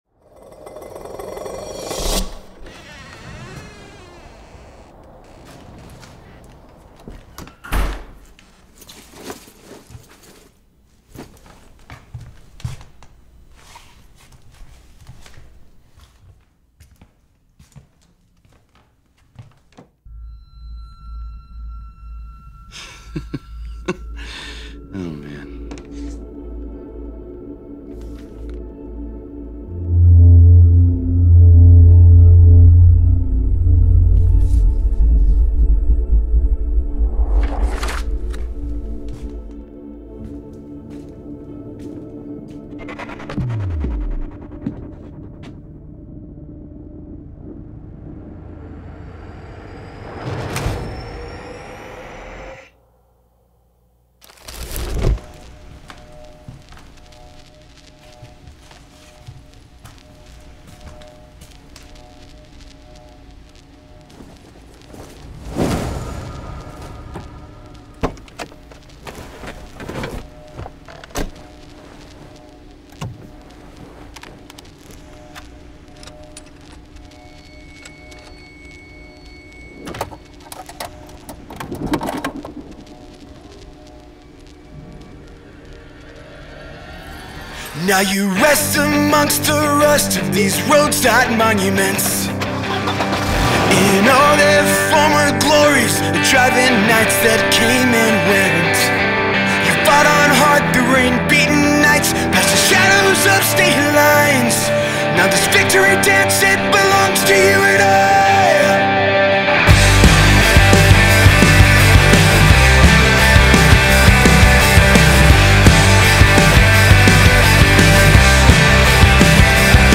Nothing forced, nothing overproduced.